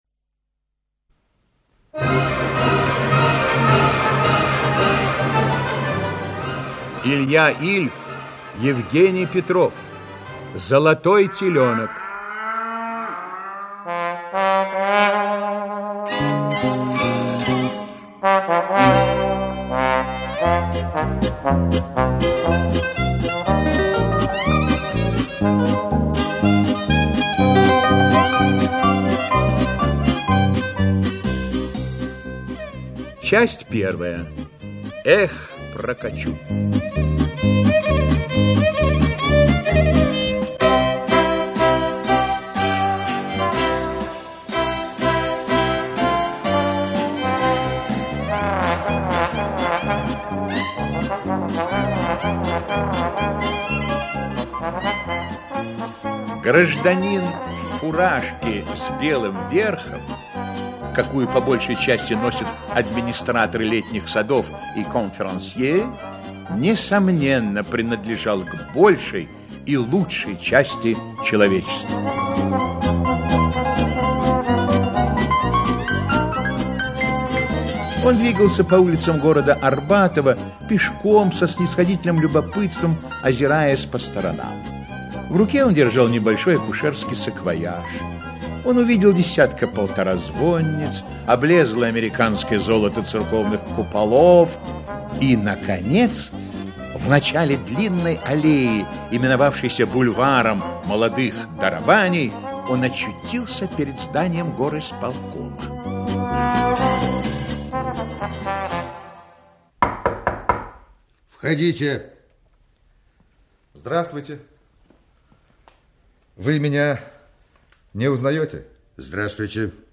Аудиокнига Золотой телёнок (спектакль) | Библиотека аудиокниг
Aудиокнига Золотой телёнок (спектакль) Автор Илья Ильф Читает аудиокнигу Актерский коллектив.